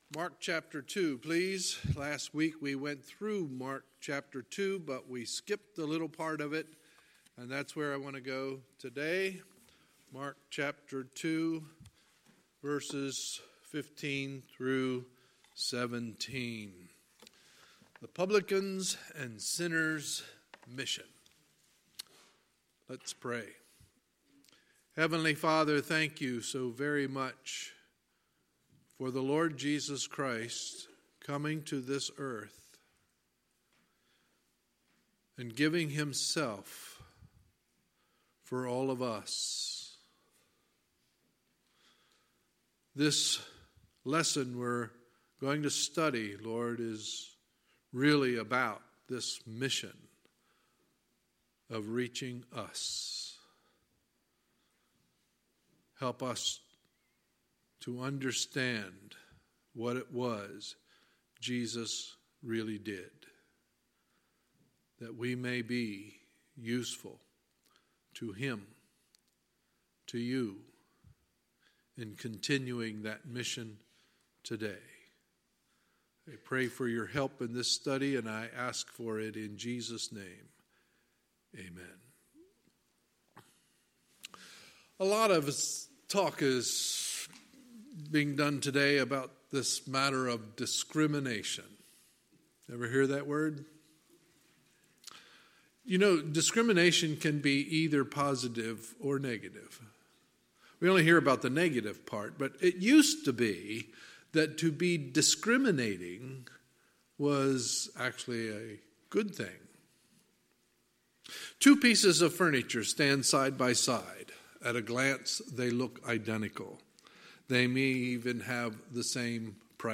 Sunday, February 24, 2019 – Sunday Morning Service